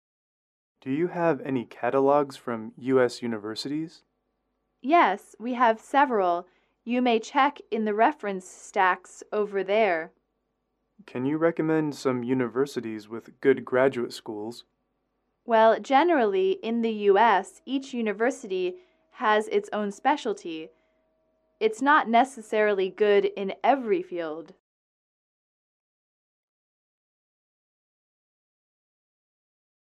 英语口语情景短对话25-4：美国的大学（MP3）